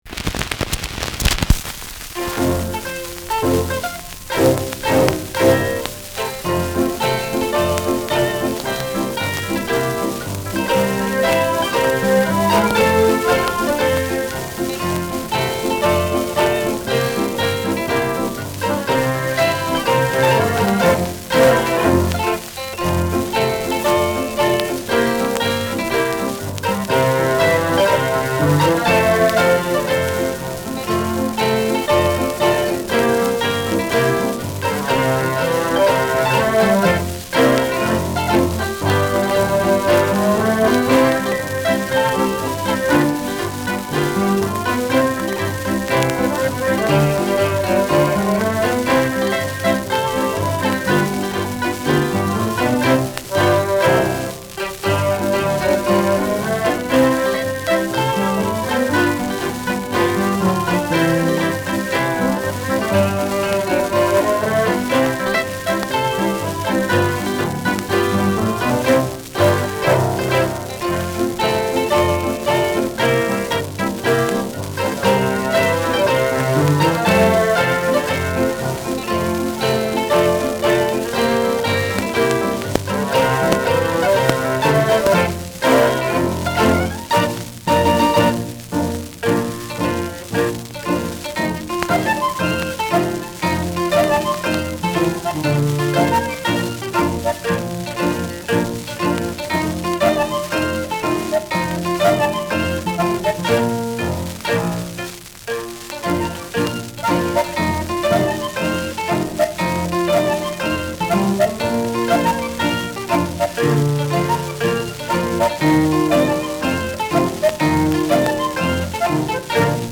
Schellackplatte
Abgespielt : Gelegentlich stärkeres Knacken
[Berlin] (Aufnahmeort)